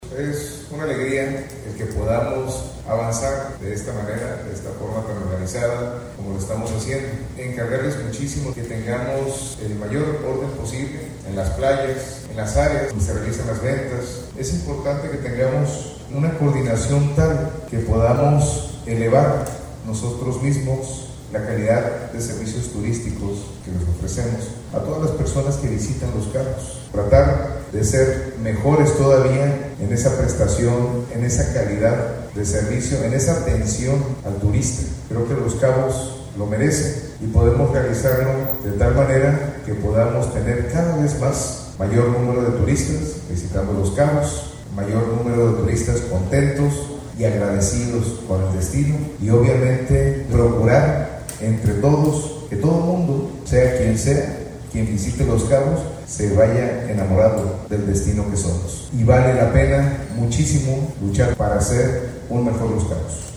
Voz: Christian Agúndez – Alcalde de Los Cabos